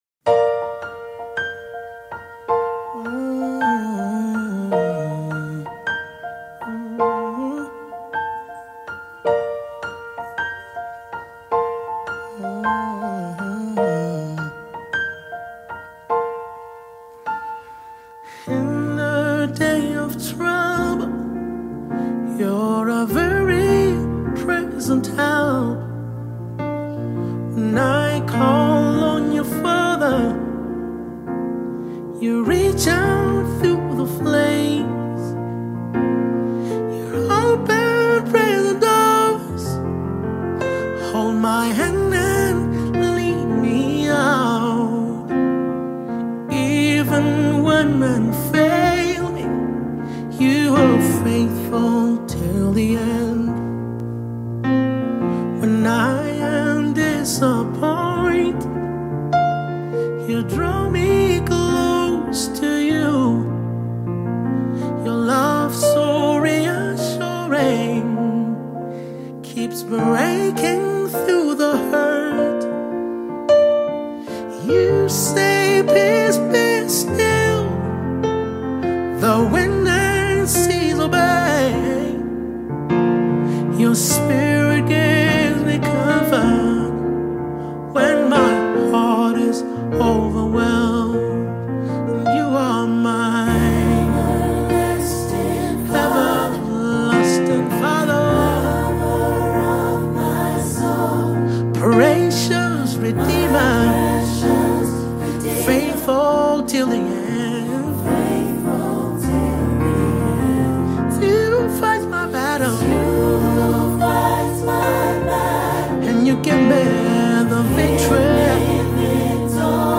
Mp3 Gospel Songs
praise and Worship